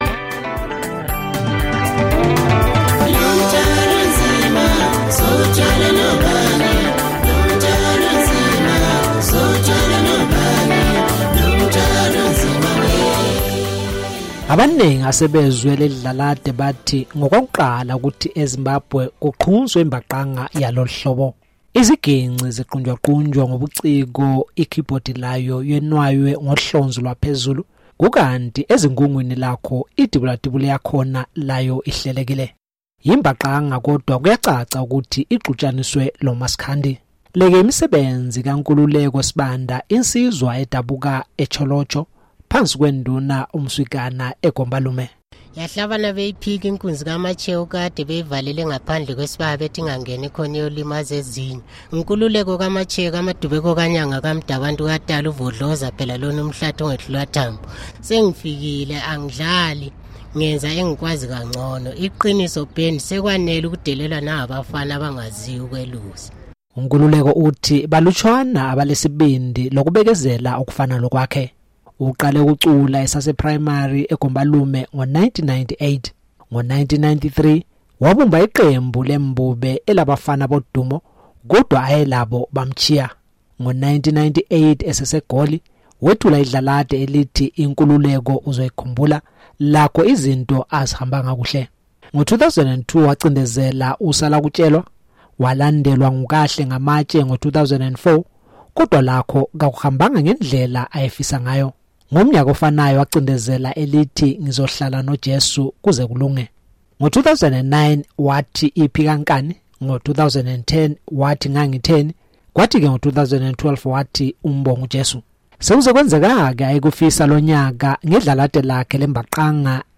Iziginci ziqunjwaqunjwe ngobuciko, ikeyboard layo yenwaywa ngohlonzi lwaphezulu kukanti ezingungwini lakho idibulatibule yakhona layo ihlelekile. YiMbaqanga kodwa kuyacaca ukuthi ixutshaniswe loMaskandi.